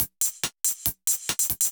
Index of /musicradar/ultimate-hihat-samples/140bpm
UHH_ElectroHatC_140-02.wav